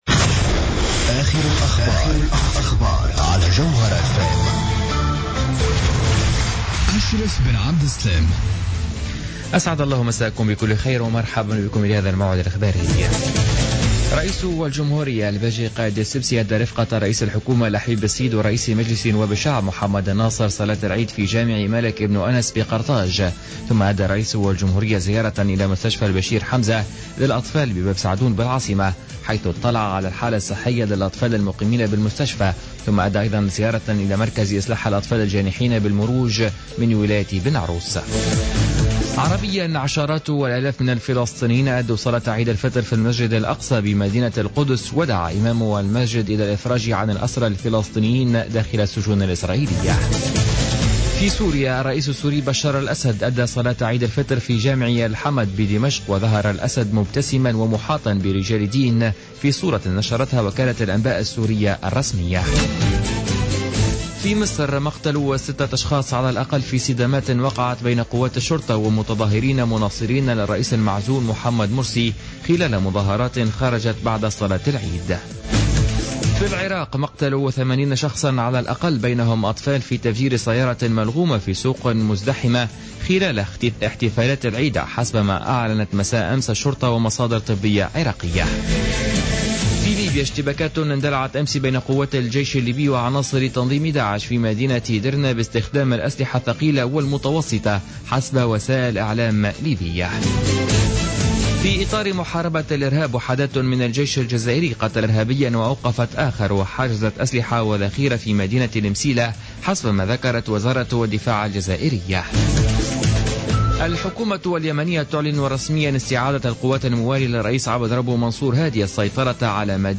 نشرة أخبار منتصف الليل ليوم السبت 18 جويلية 2015